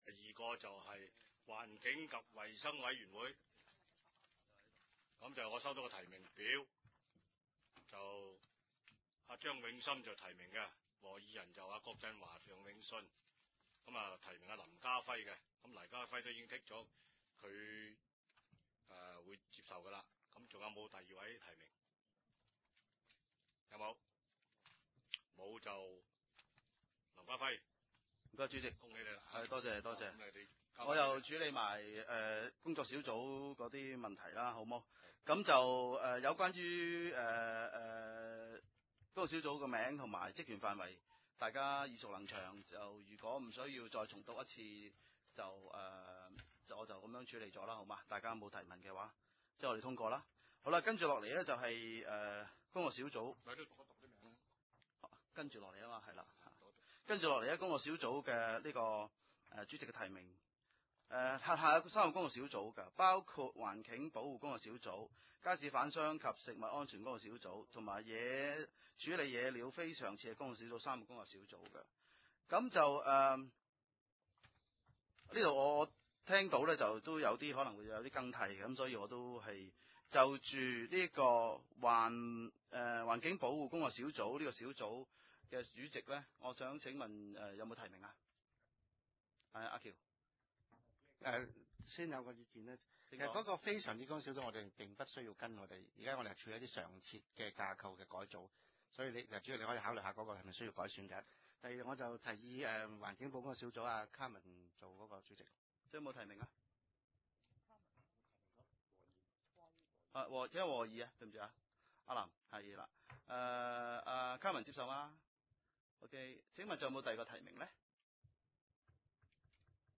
境及衞生委員會特別會議議程
地點：九龍長沙灣道303號長沙灣政府合署4字樓
深水埗區議會會議室